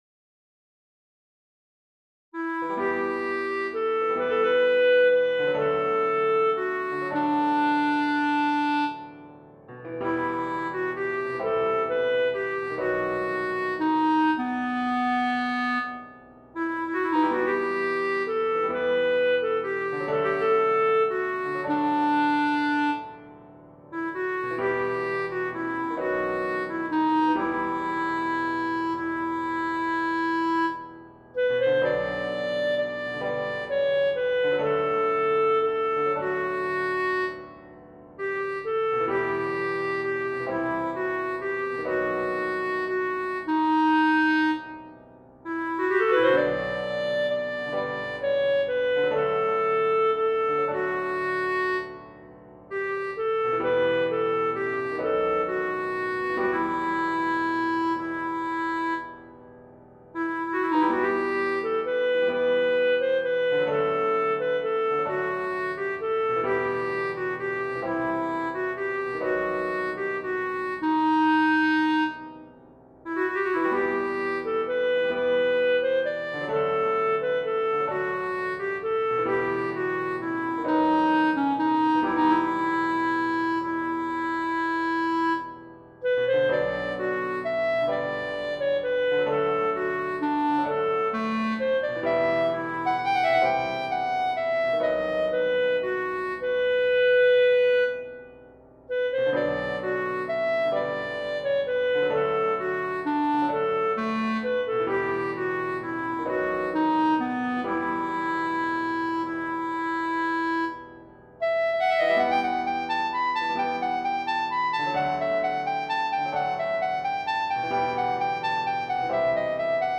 SATB , Clarinet